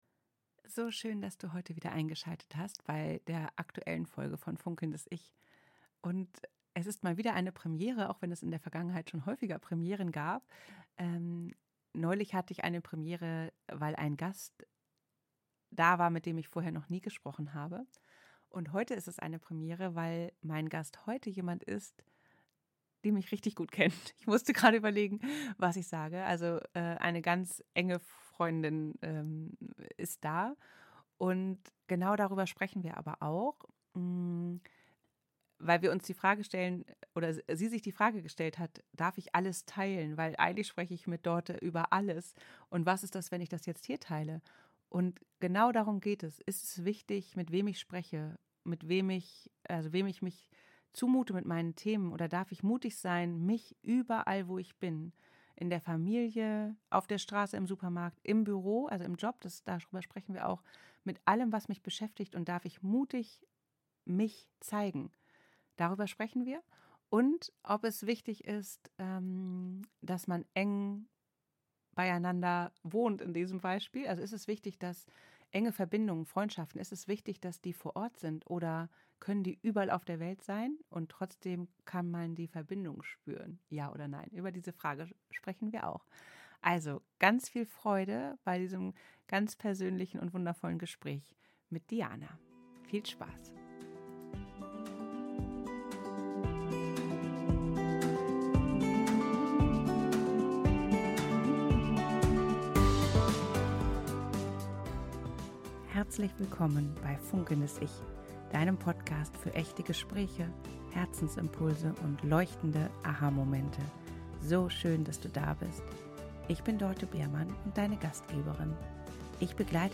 Ein Gespräch über das Menschsein in all seinen Facetten: im Beruf in Freundschaft in Familie.